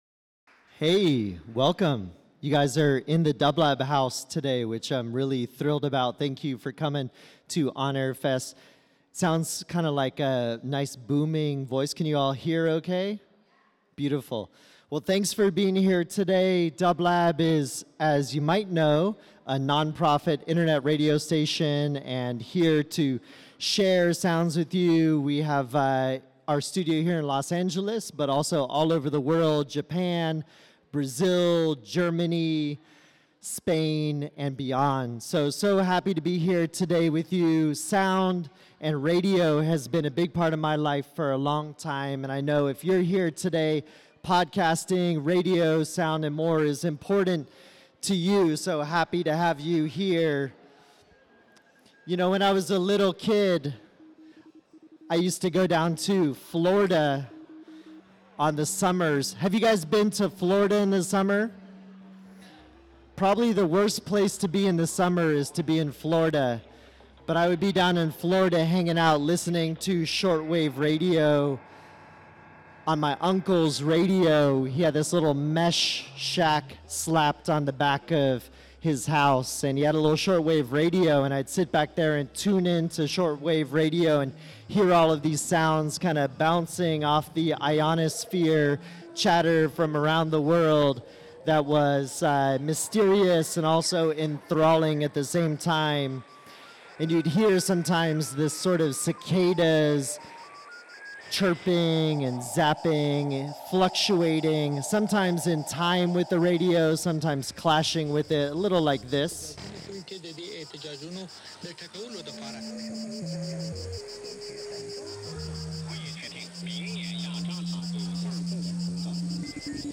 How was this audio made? In this live mix session of broadcasts from around the world